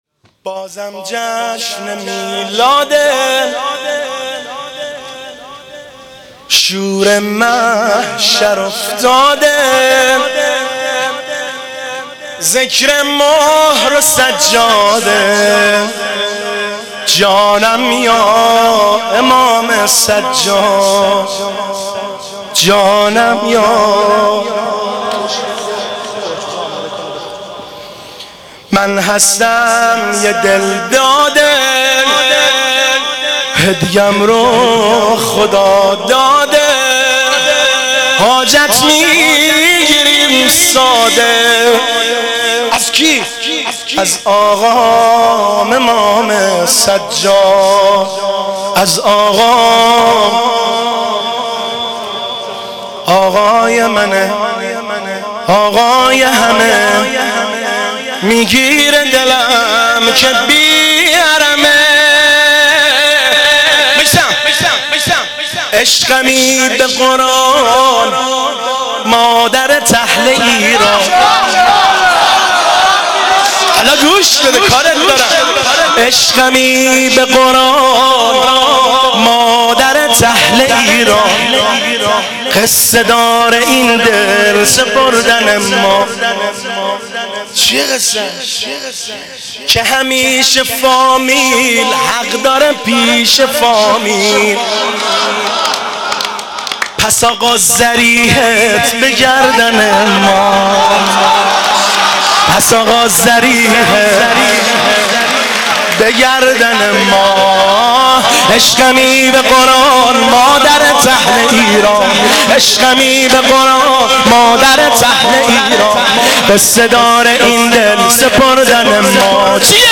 قالب : سرود